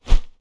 Close Combat Swing Sound 17.wav